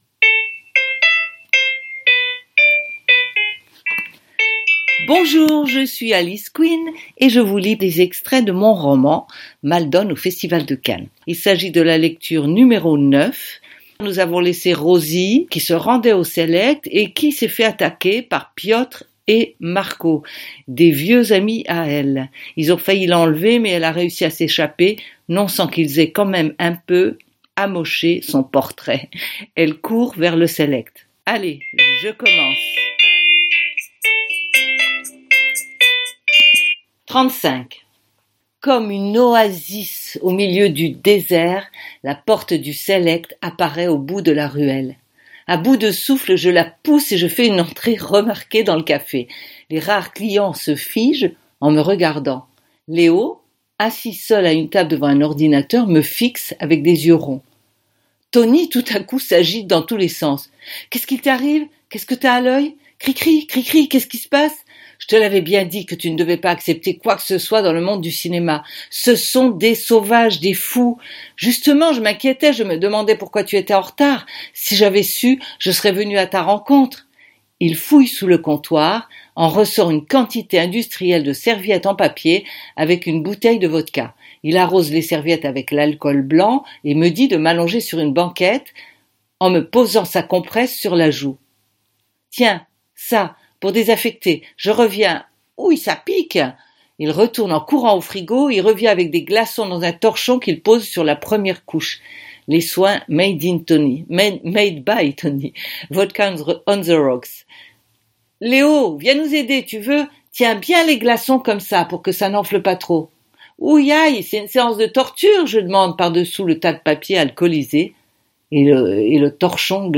Lecture #9 Ce roman fait partie de la série AU PAYS DE ROSIE MALDONNE.